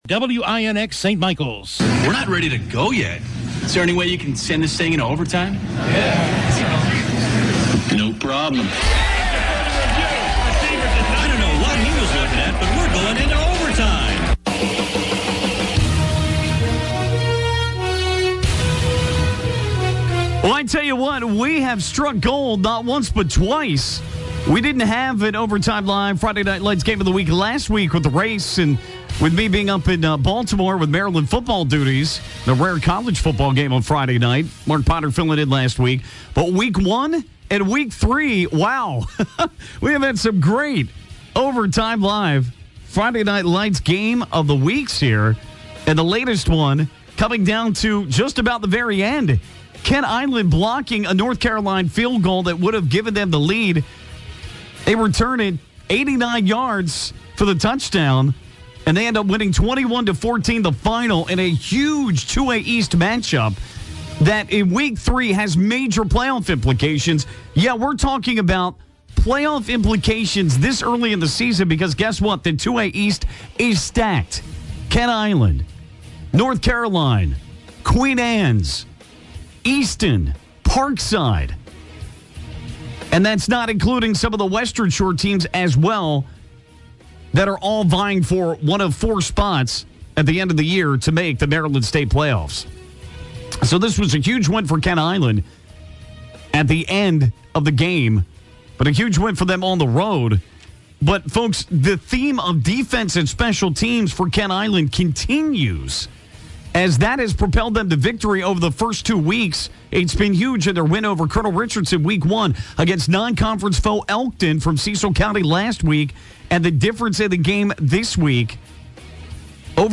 chats with coaches